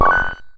Sound effect of Coin in Mario Bros..